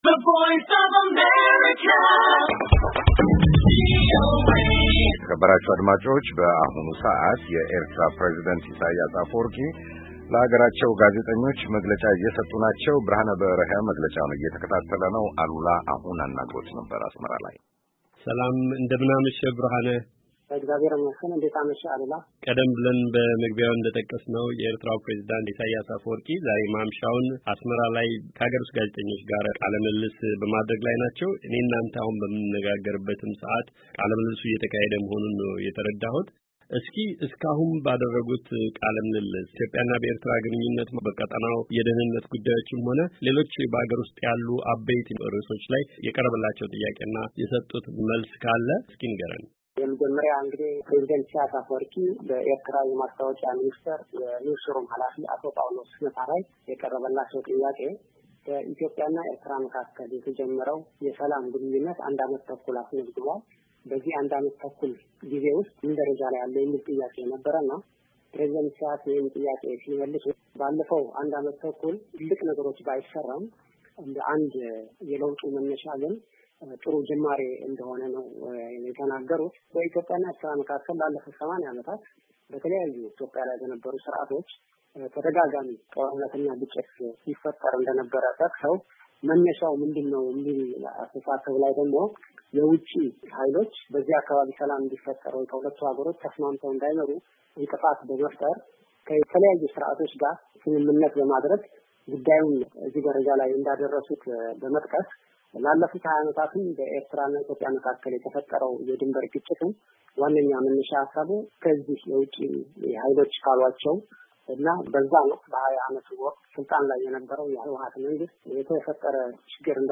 የፕሬዚዳንት ኢሳያስ ቃለ ምልልስ
የኤርትራ ፕሬዚዳንት ኢሳያስ አፈወርቂ በሀገራቸው ቴሌቪዝን ቀርበው ከጋዜጠኞች ለቀረቡላቸው ጥያቄዎች መልስ ሰጥተዋል።